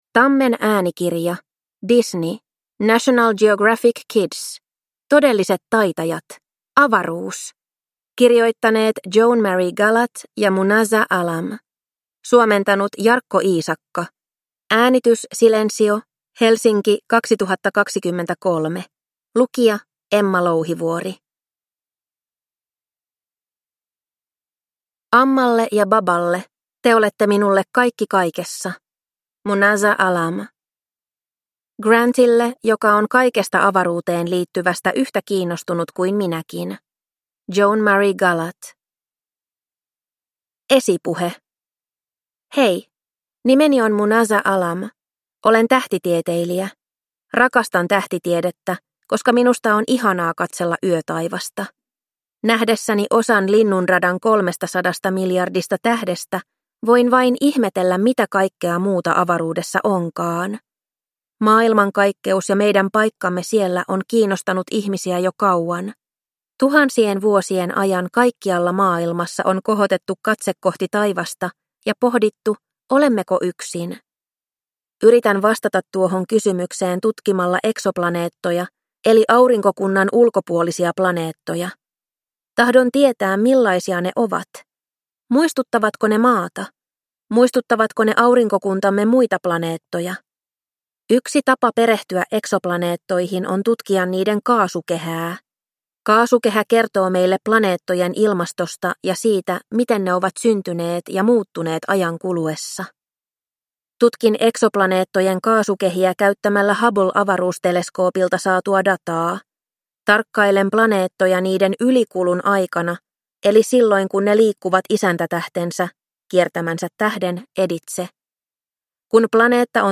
Todelliset taitajat. Avaruus – Ljudbok